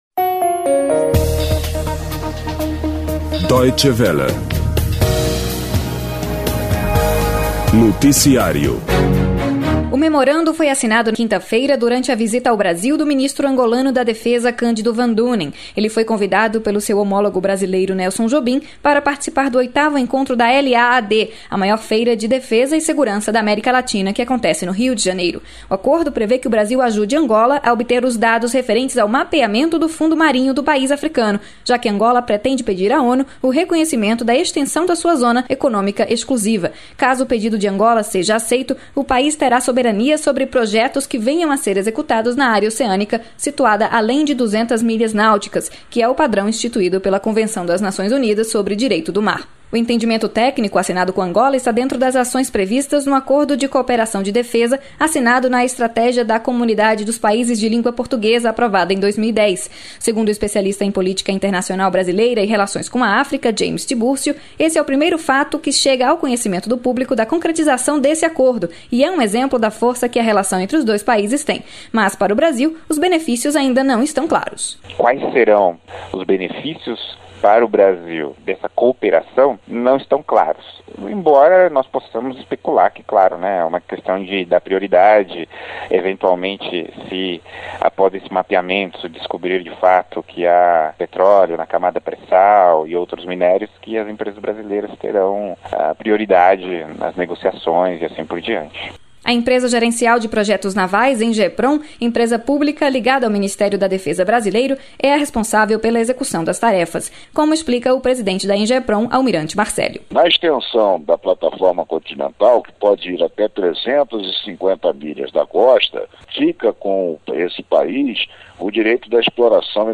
Boletim Deutsche Welle Radio